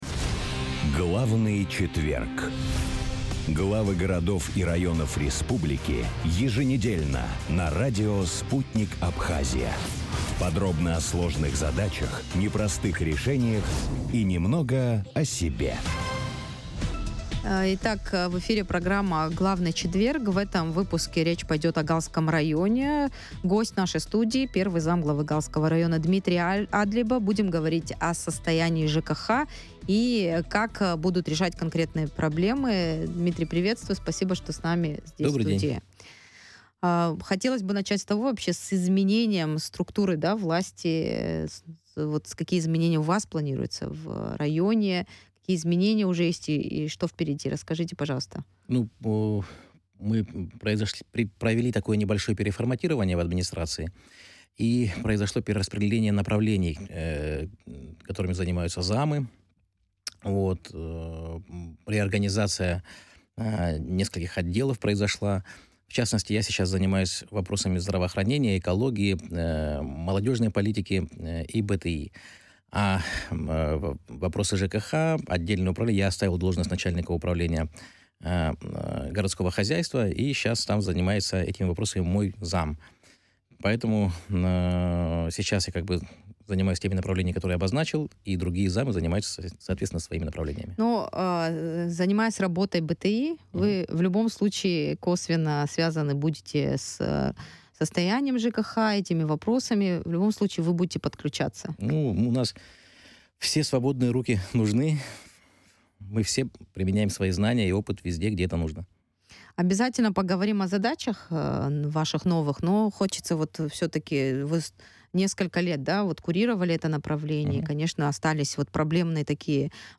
Замглавы администрации Галского района Дмитрий Адлейба в эфире рдио Sputnik рассказал о состоянии жилищно-коммунального хозяйства в преддверие курортного сезона, о том, как решается вопрос с утилизацией бытовых отходов, каковы планы по...